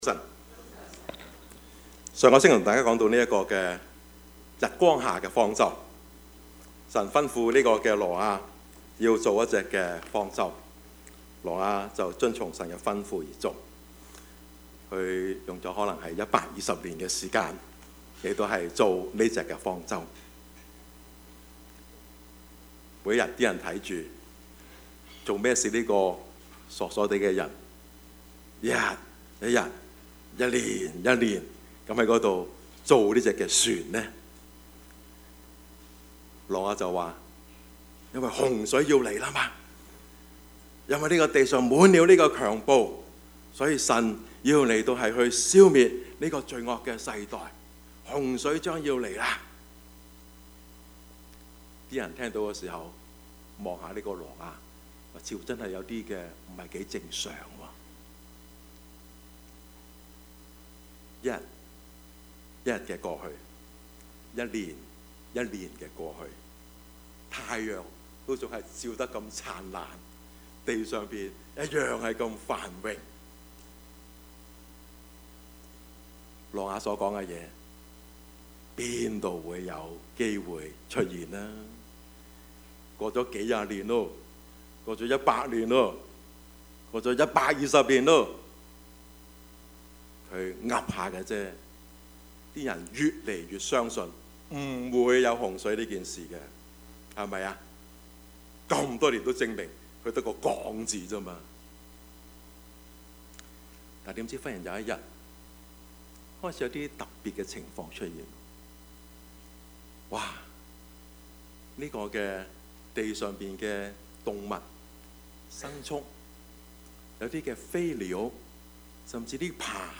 Topics: 主日證道 « 晚清的袁世凱 聽得入耳 »